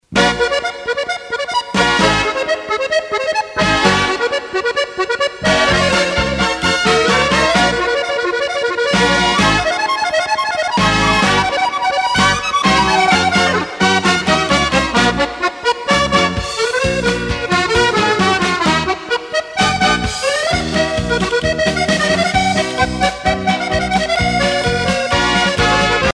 danse : paso musette